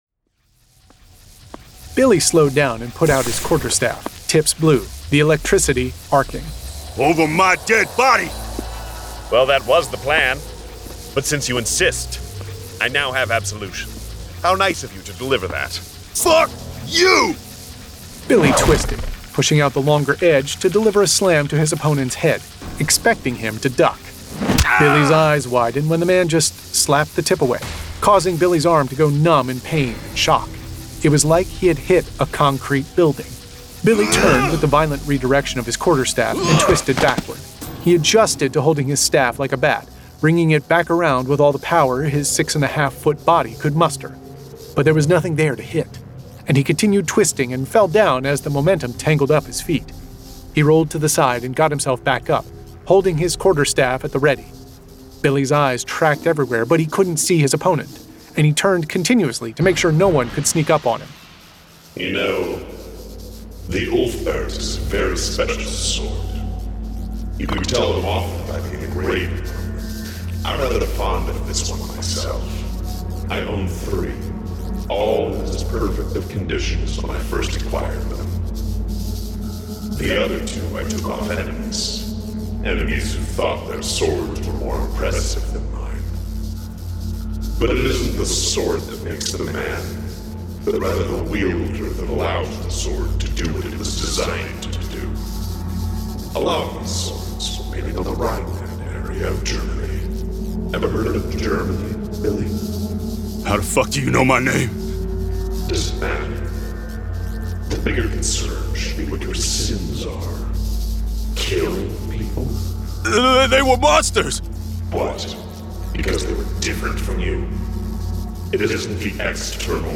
Full Cast. Cinematic Music. Sound Effects.
Genre: Science Fiction